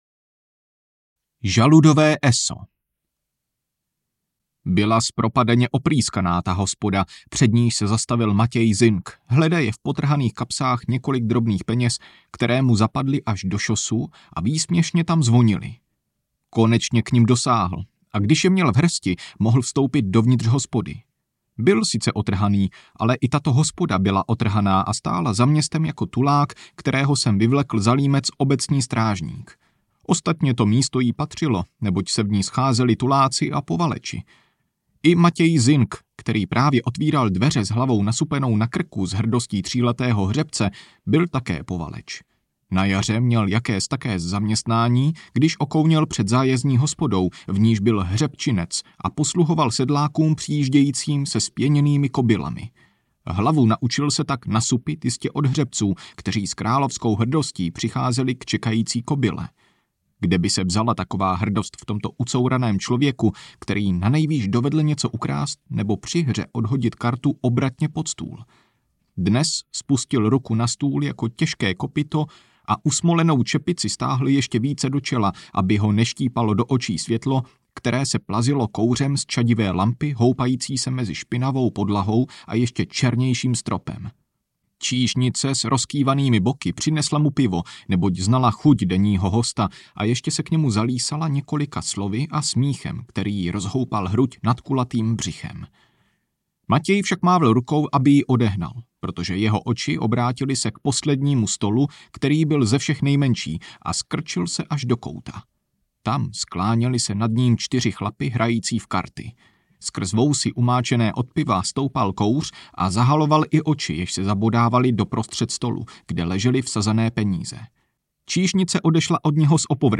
Bojácní a rváč audiokniha
Ukázka z knihy